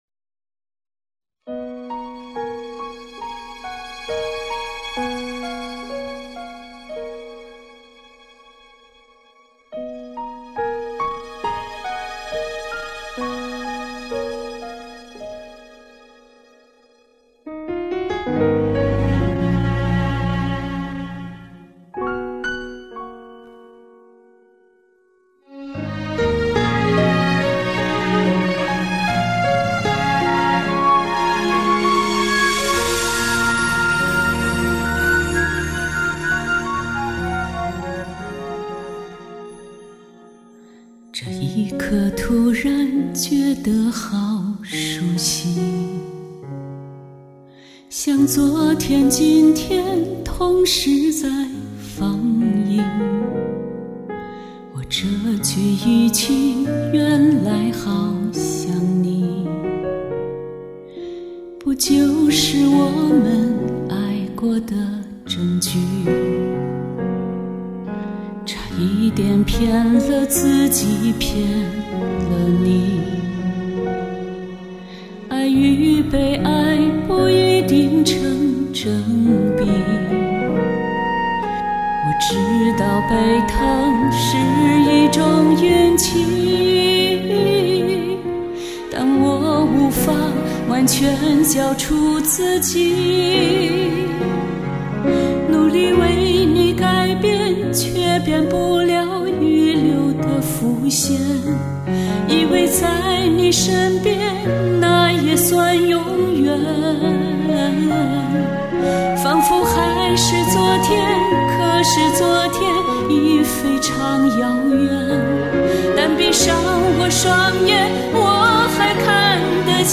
翻唱：我